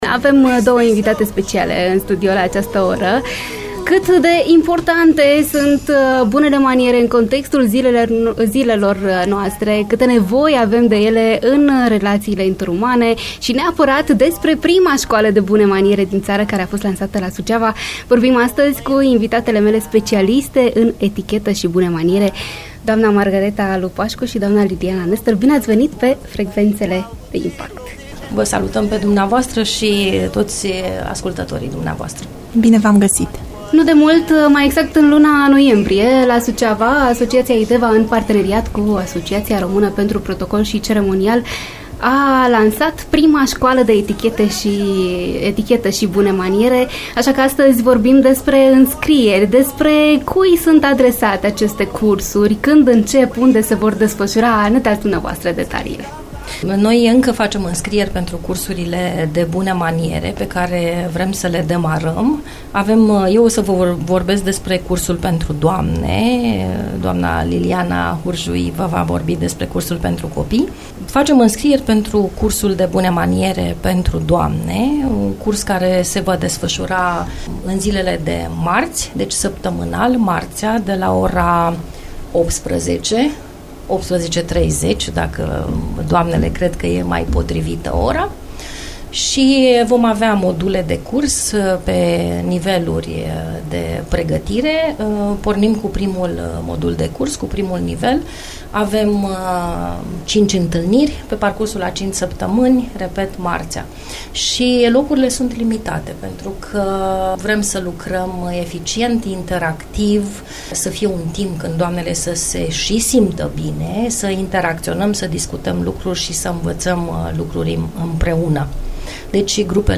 live la IMPACT FM Suceava